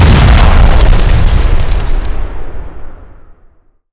defensivesystemspenetrated.wav